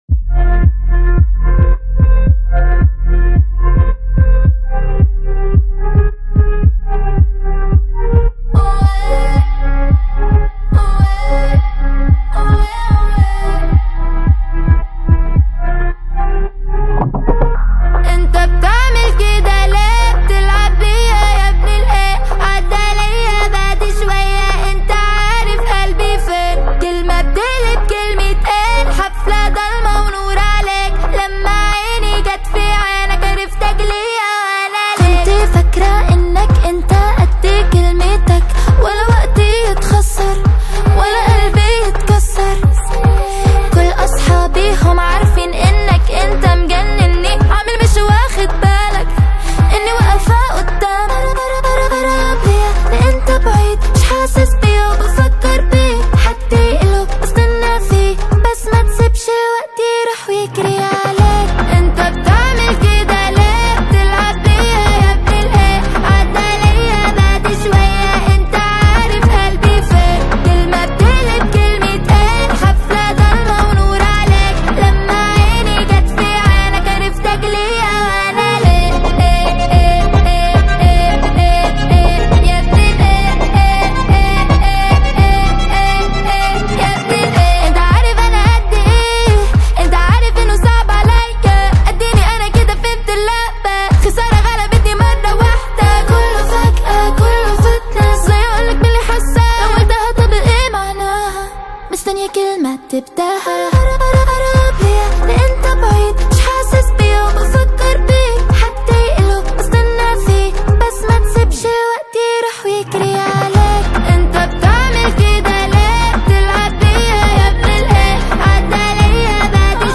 اهنگ عربی
شاد عربی